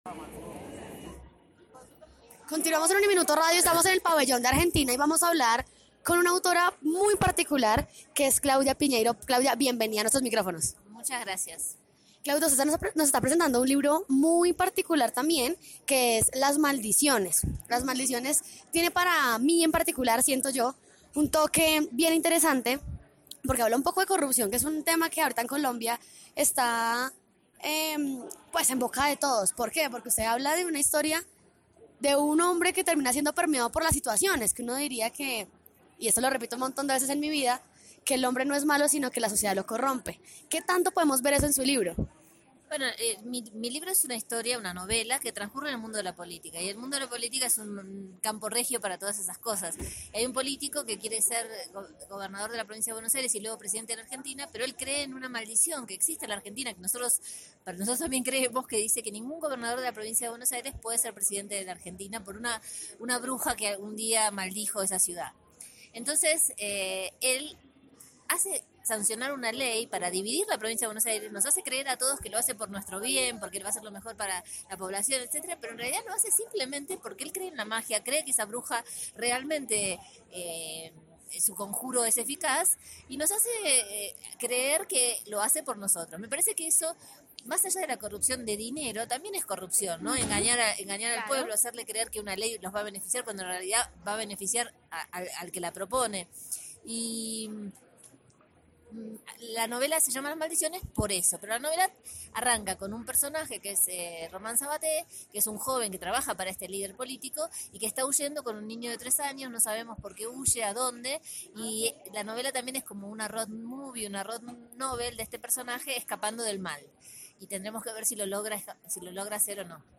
En el pabellón del país invitado de honor de la FILBo 2018, Claudia Piñeiro habló sobre ‘Las maldiciones’, libro novedad en la Feria Internacional del Libro de Bogotá, relato con el que expone la paternidad, la corrupción y una antigua creencia argentina.